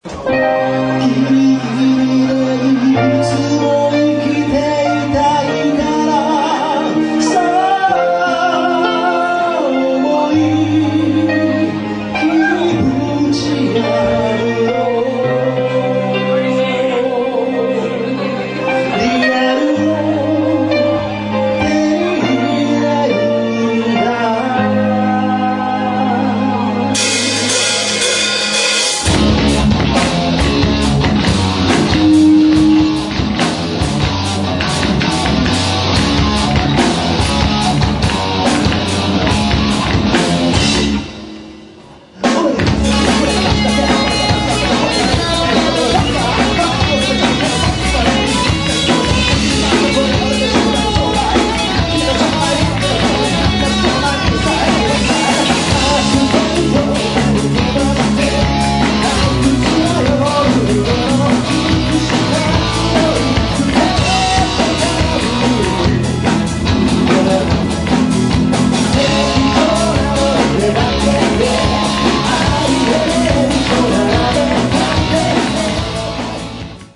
結構ハードでいい感じ。
採用はしたものの、キメやブレイクが異様に多くラップまであり、一時は実現を危ぶんで保険の曲を用意したほどです。